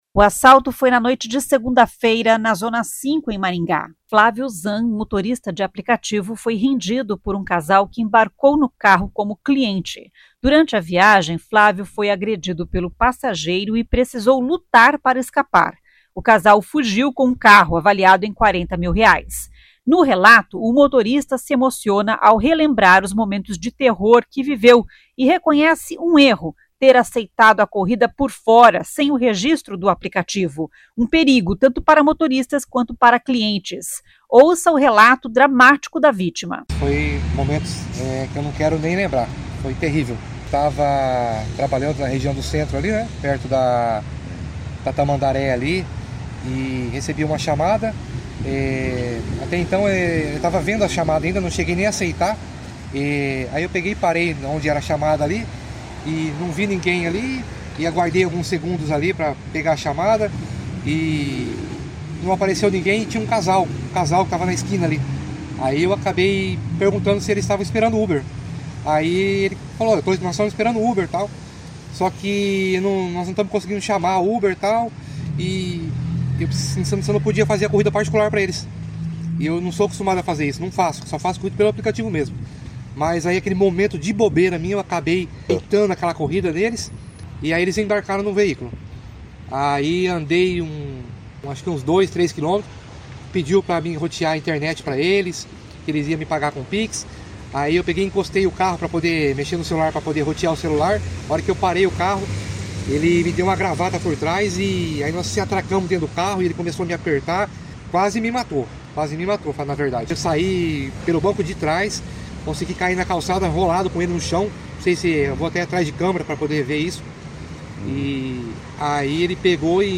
No relato, o motorista se emociona ao relembrar os momentos de terror que viveu e reconhece um erro: ter aceitado a corrida por fora, sem o registro do aplicativo.